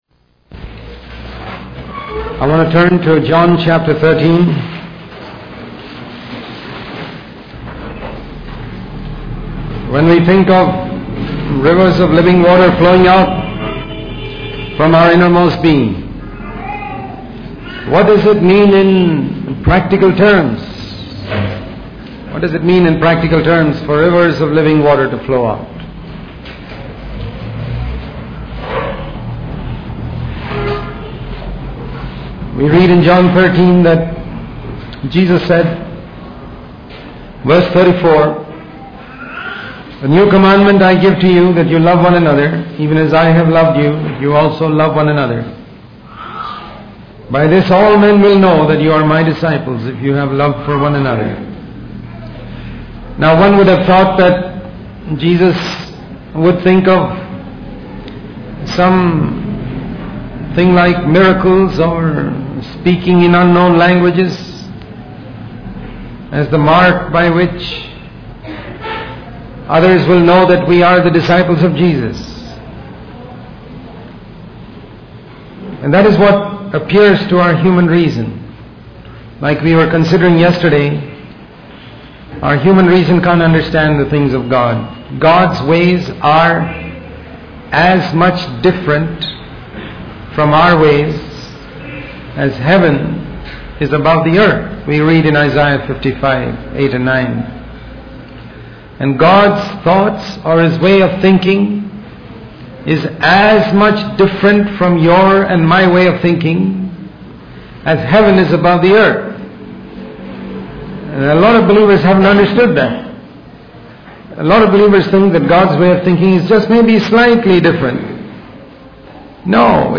In this sermon, the speaker emphasizes the importance of love and unity among believers. He challenges the idea that miracles and signs are the ultimate proof of being God's people. Instead, he argues that the greatest miracle is to love one another and remain in love until the end.